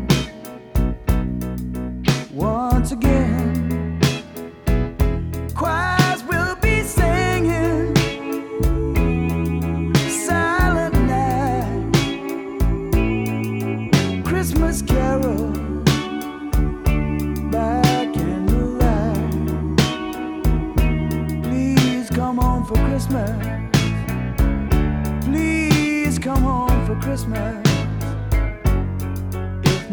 • Holiday